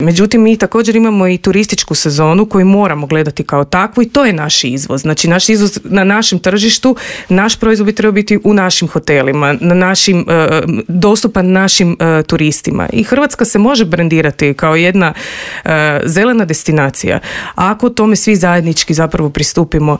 O tome kako spasiti poljoprivrednike, razgovarali smo u Intervjuu Media servisa s voditeljicom Odjela za poljoprivrednu politiku